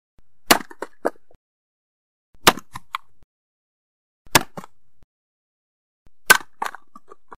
Звуки ударов по дереву
Скоростная рубка деревьев